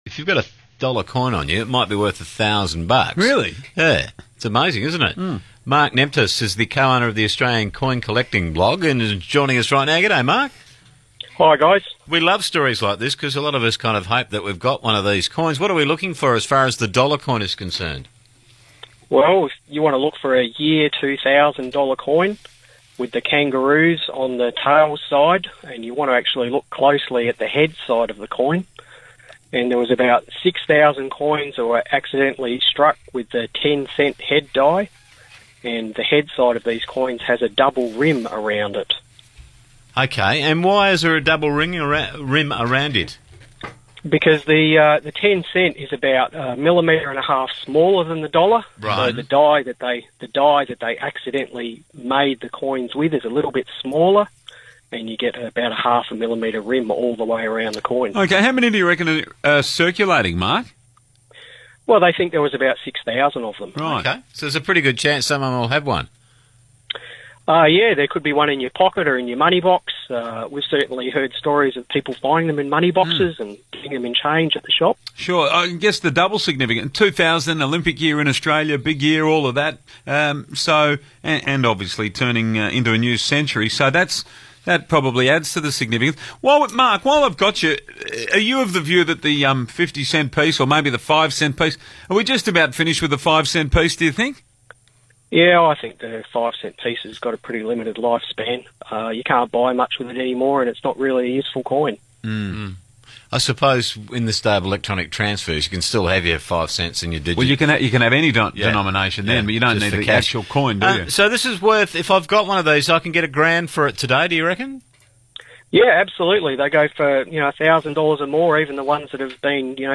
Is your Dollar Coin Worth $1000? – Radio Interview with 6PR in Perth